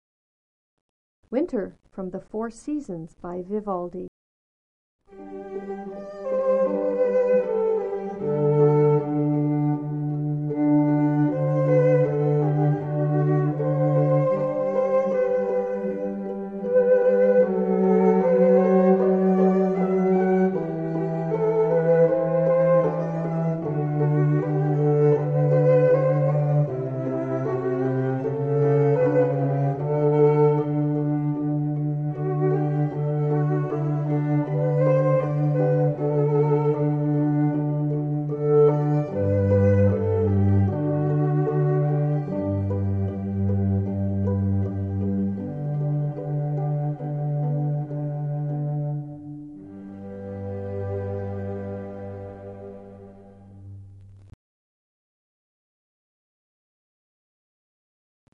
Winter From 4 Seasons (Vivaldi) - Allegro Quartet : Hire Wedding String Quartets Arizona : Classical Wedding Ceremony Musicians : Live Music Orchestras : Violin String Quartet Phoenix, Scottsdale
Genre: Classical.